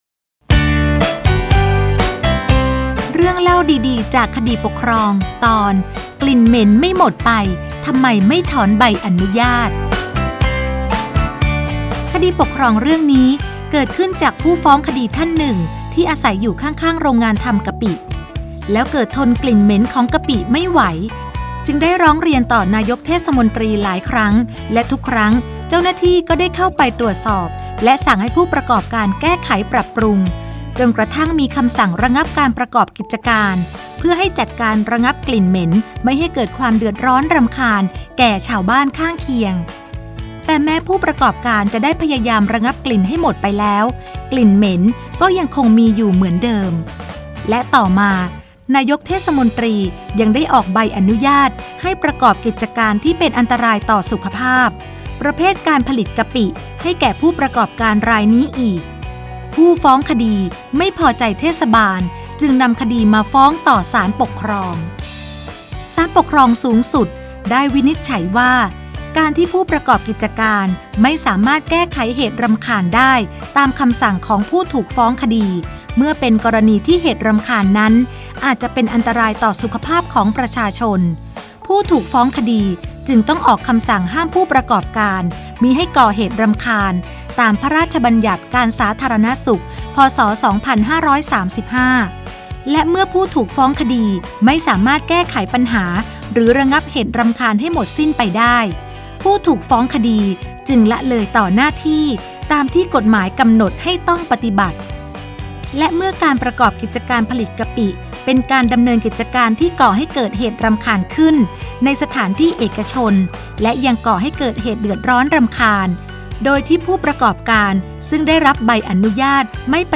คำสำคัญ : กลิ่นเหม็นไม่หมดไปทำไมไม่ถอนใบอนุญาต, คำพิพากษา, เรื่องเล่าดีดีจากคดีปกครอง, สารคดีวิทยุ, คดีปกครอง, ตัวอย่างคดี